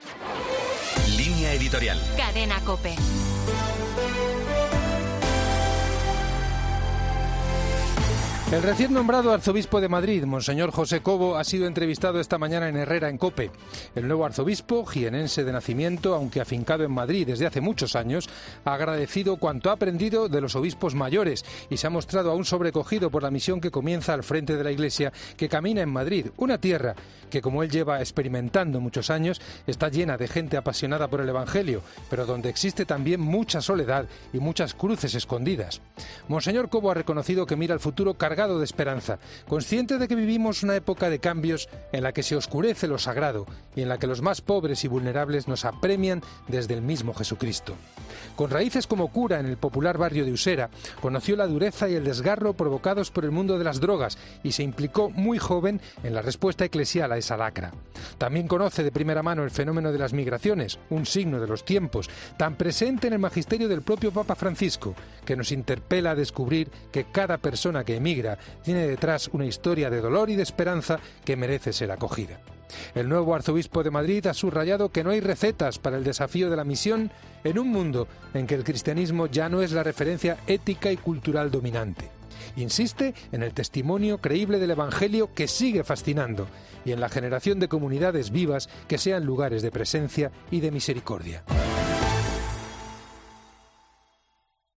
El recién nombrado arzobispo de Madrid, monseñor José Cobo, ha sido entrevistado esta mañana en Herrera en COPE